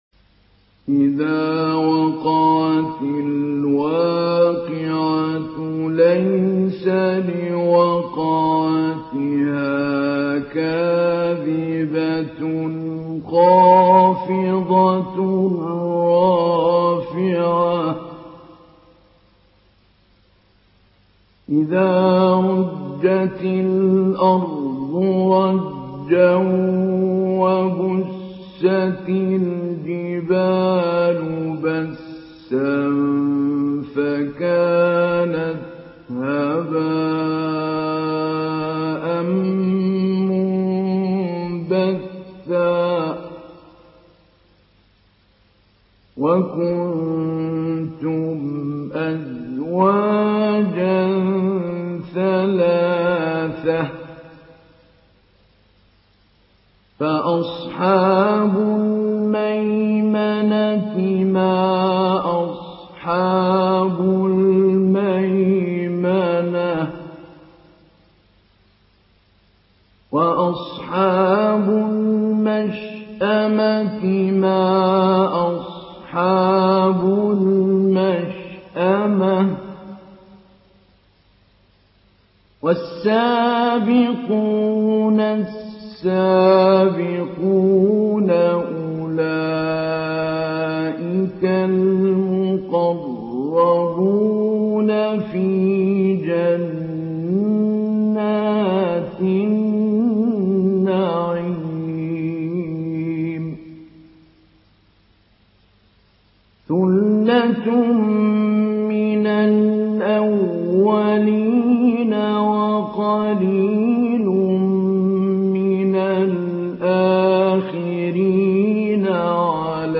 Surah Al-Waqiah MP3 in the Voice of Mahmoud Ali Albanna Mujawwad in Hafs Narration
Surah Al-Waqiah MP3 by Mahmoud Ali Albanna Mujawwad in Hafs An Asim narration.